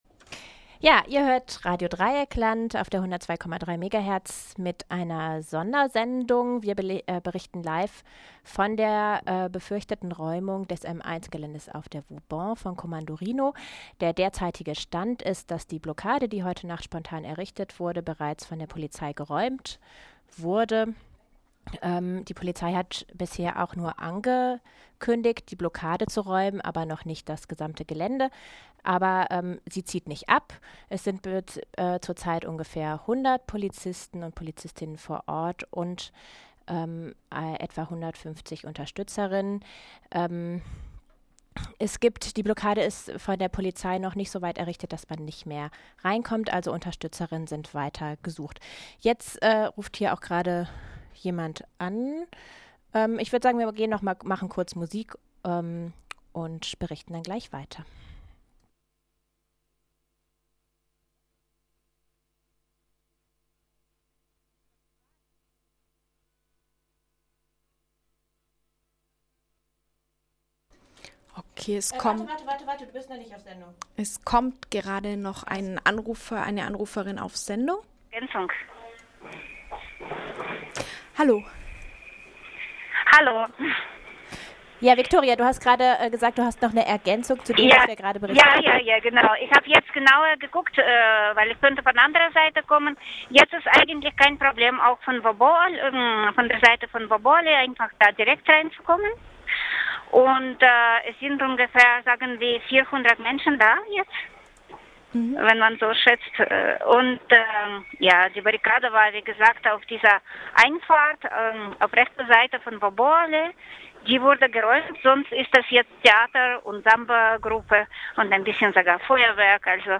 RDL Berichte